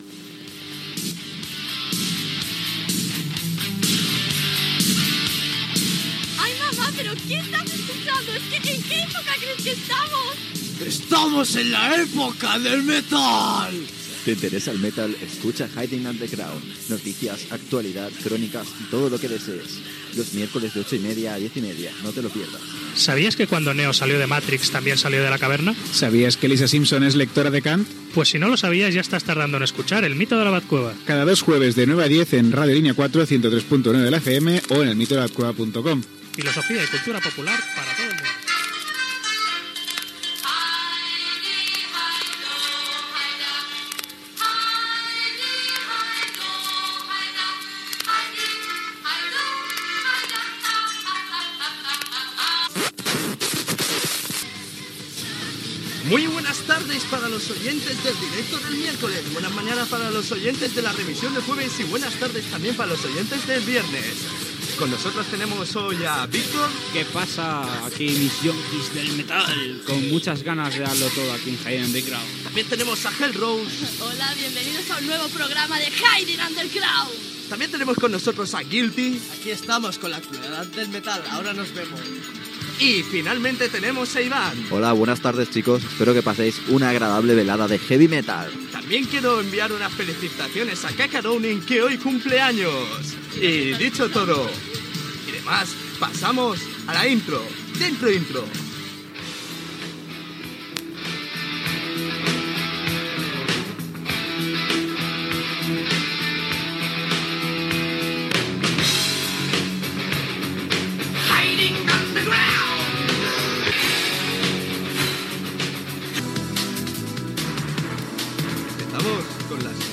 Careta del programa, promoció del programa "El mito de la cueva", salutació de l'equip del programa i notícies.
FM